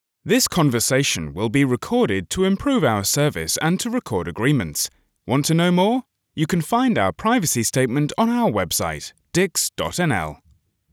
English (British)
IVR
Custom-built home studio